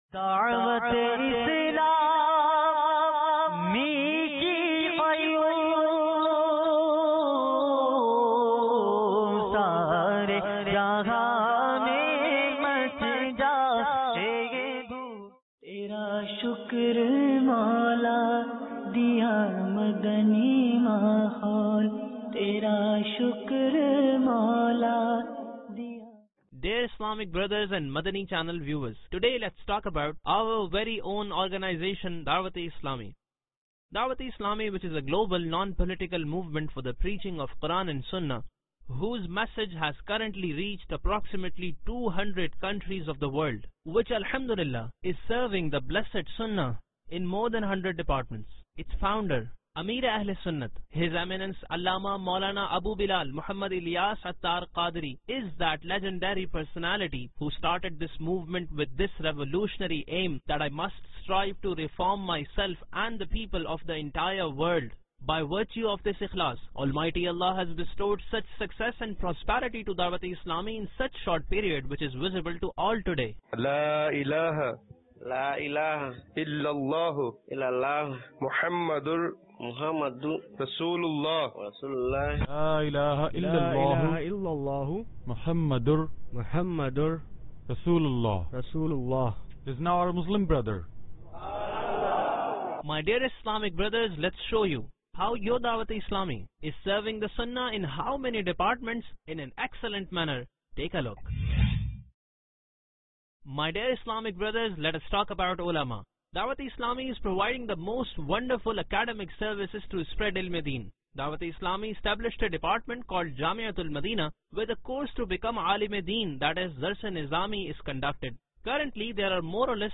Documetary - Introduction Of Dawateislami (Dubbing) May 20, 2017 MP3 MP4 MP3 Share This is a Dubbed Documentary in English about the Introduction of Dawat-e-Islami in which you will get to know brief knowledge about some of the major departments of Dawat-e-Islami.